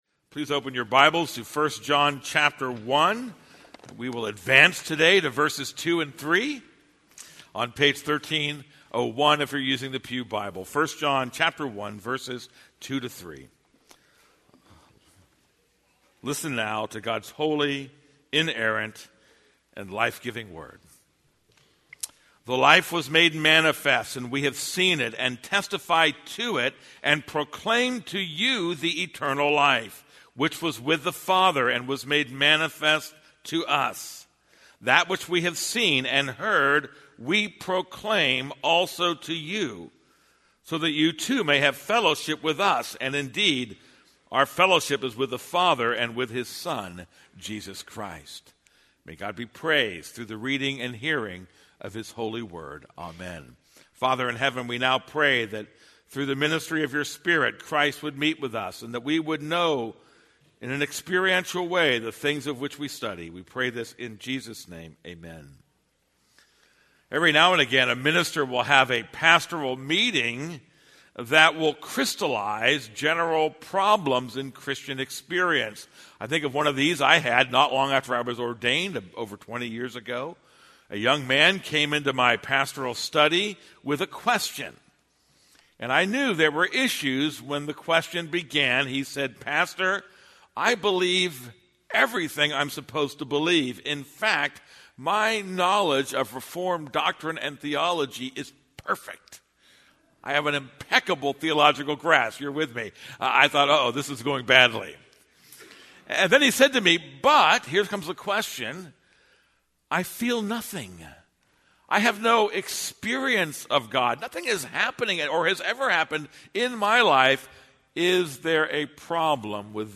This is a sermon on 1 John 1:2-3.